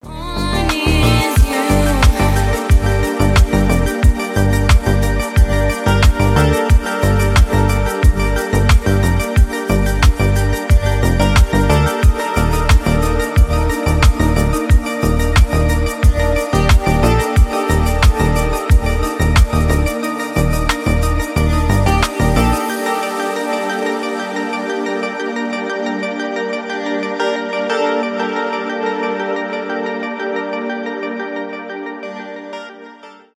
lounge , танцевальные
chillout